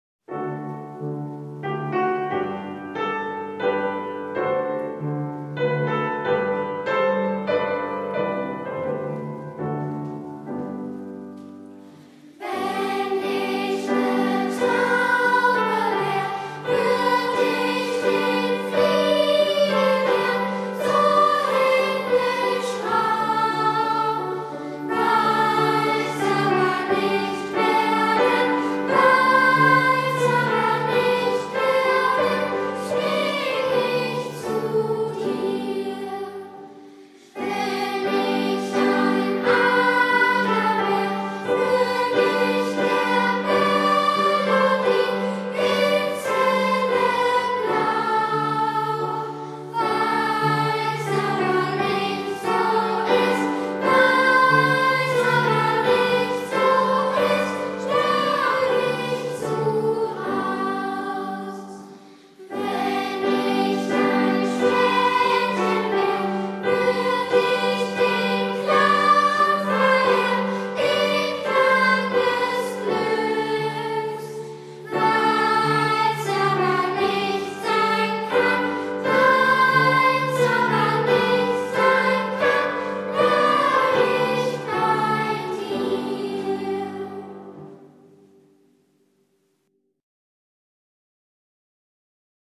Lied